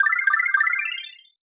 Sound effect of Starman Low in New Super Mario Bros. Wii